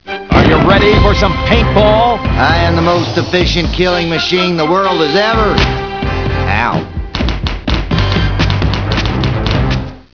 koh20710.mov (818k, Quicktime)   Audio Promo